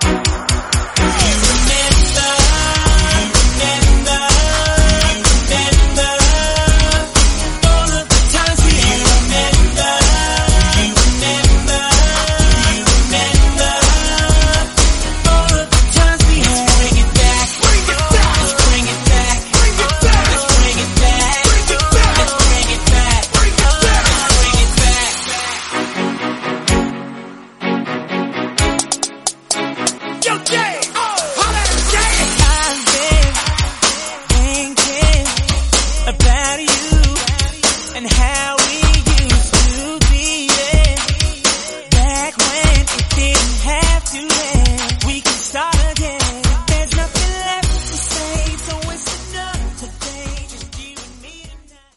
128bpm Dj Intro Outro